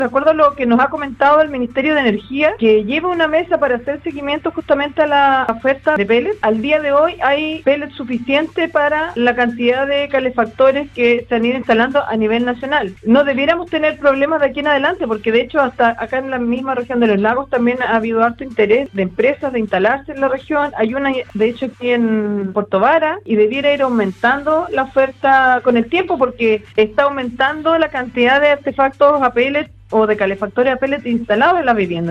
En entrevista con radio Sago, la seremi de Medio Ambiente, Carola Iturriaga, sostuvo que hay pellet suficiente para la cantidad de calefactores instalados a nivel nacional, considerando las regiones que tienen su plan de descontaminación como Osorno, además de prever que irá creciendo la oferta, debido al interés por crear nuevas empresas productoras del material combustible.